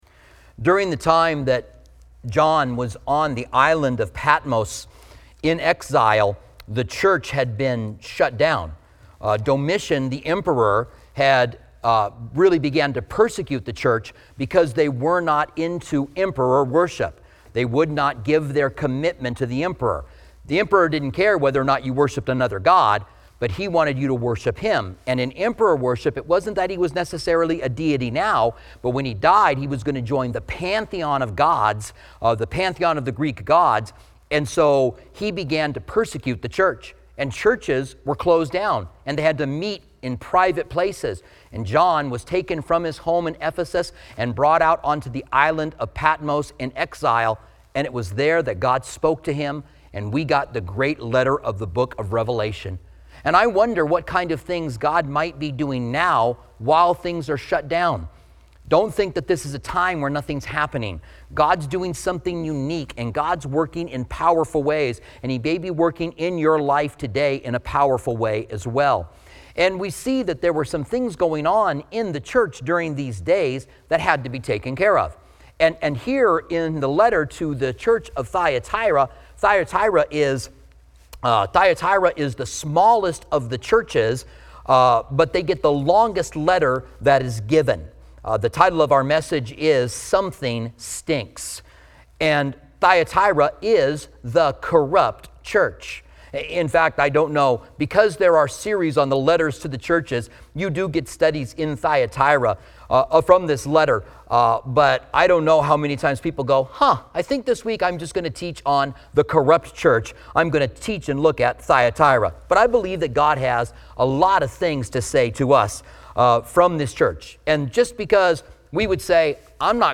The Church